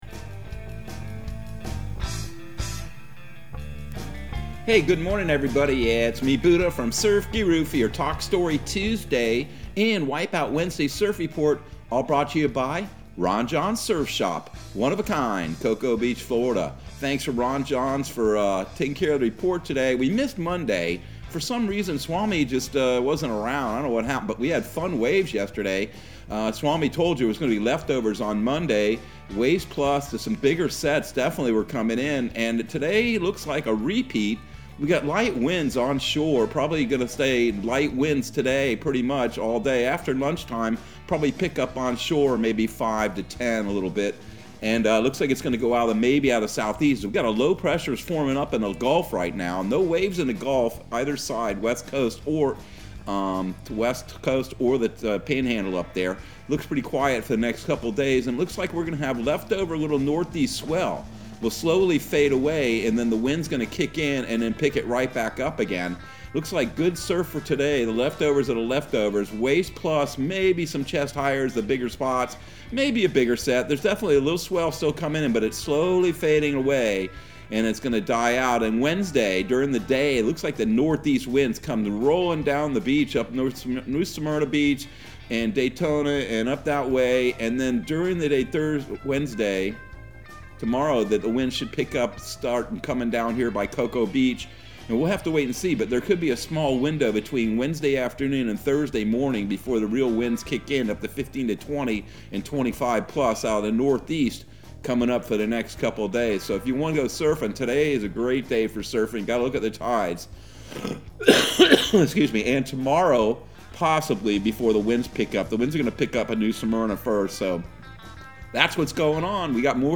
Surf Guru Surf Report and Forecast 01/25/2022 Audio surf report and surf forecast on January 25 for Central Florida and the Southeast.